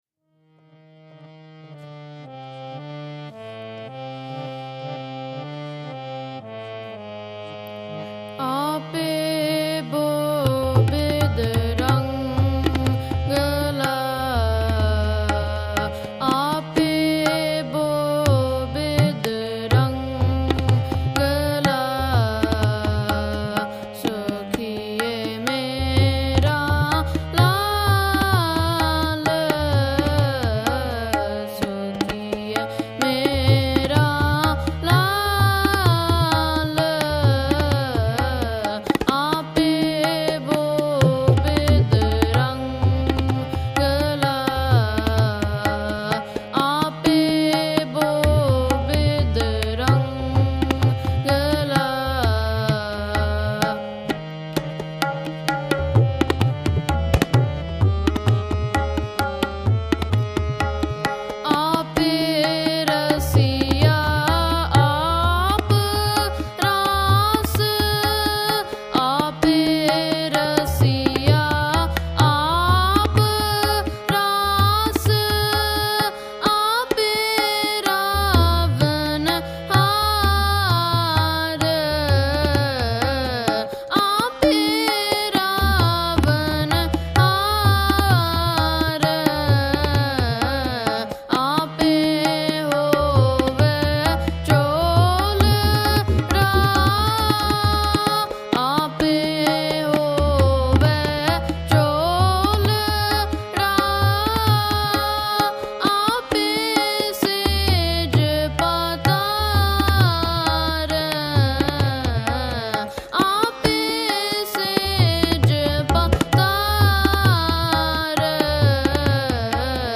High Definition recordings of contemporary Gurmat Sangeet
at Scarborough Gurdwara on May 21 2011